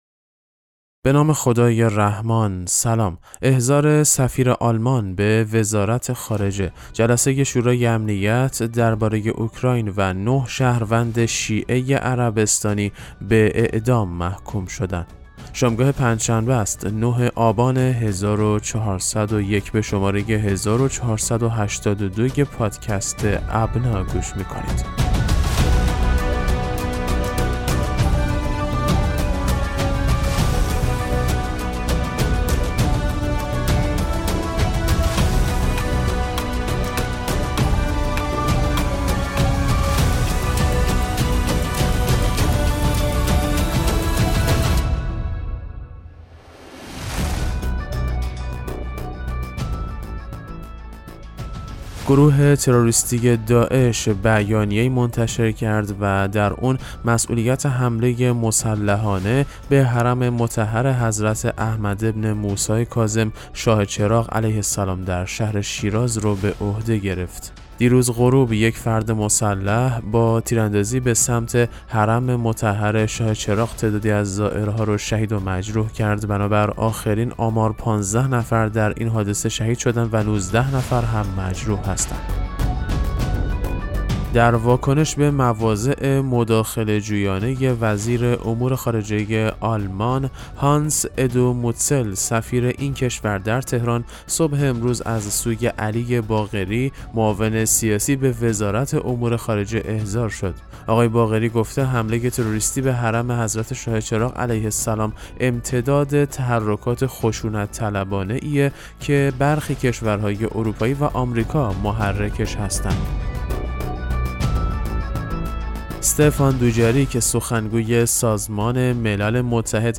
پادکست مهم‌ترین اخبار ابنا فارسی ــ 5 آبان 1401